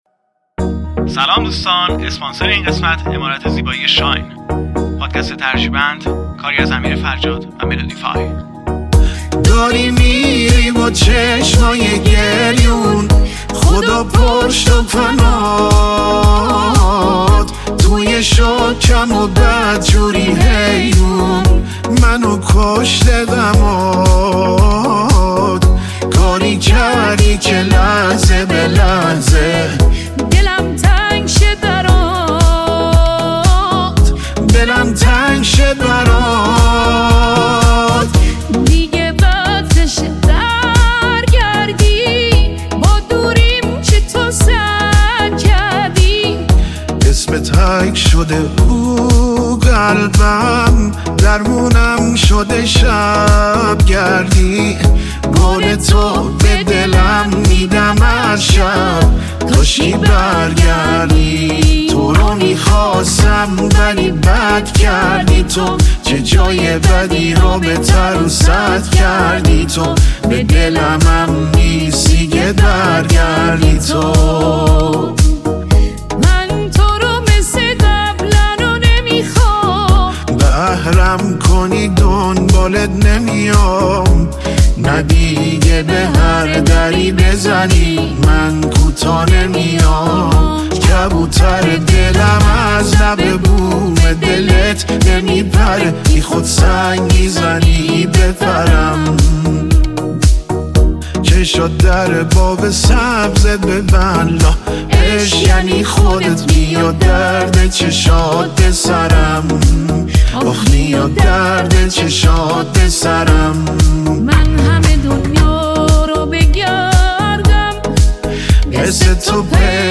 ژانر: ریمیکس